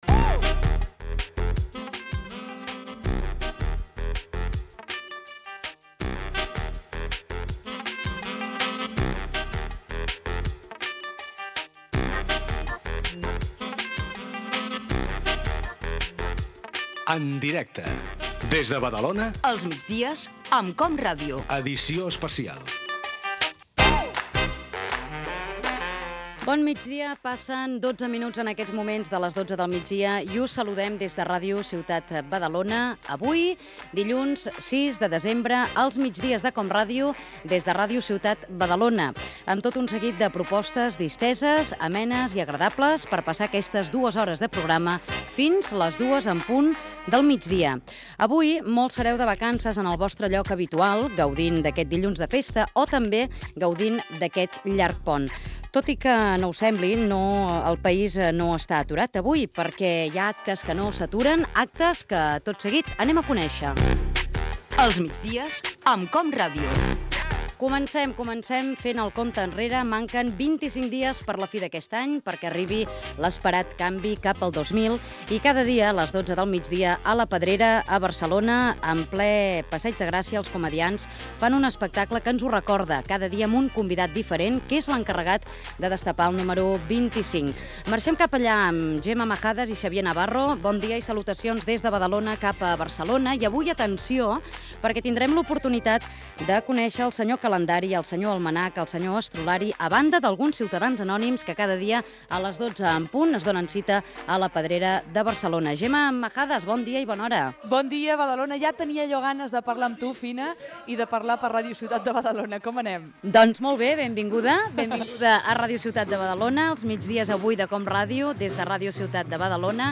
865fd24576b1de3473eca7f2861b99c66098b220.mp3 Títol COM Ràdio Emissora Ràdio Ciutat de Badalona Cadena COM Ràdio Titularitat Pública municipal Nom programa Els migdies amb COM Ràdio Descripció Careta del programa, presentació.
Connexió amb la unitat mòbil que està a l'espectacle diari de Els Comediants a la Pedrera de Barcelona.
Sumari del programa. Gènere radiofònic Entreteniment